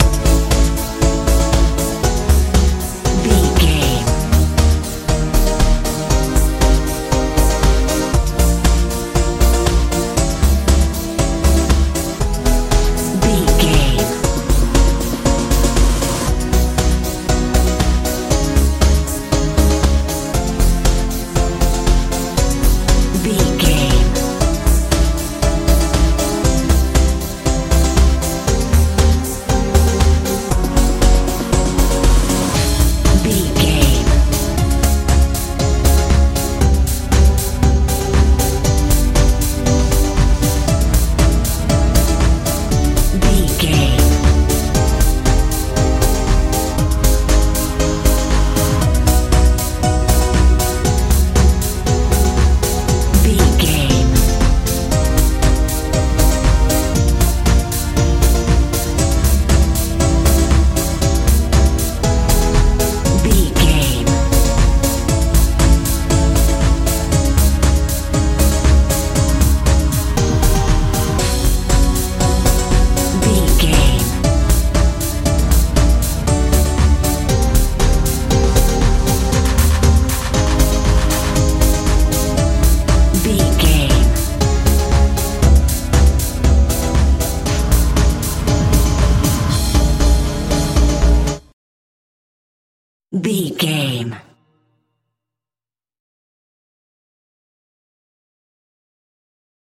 techno house feel
Ionian/Major
bright
magical
synthesiser
bass guitar
drums
80s
90s